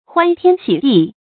huān tiān xǐ dì
欢天喜地发音
成语正音地，不能读作“de”。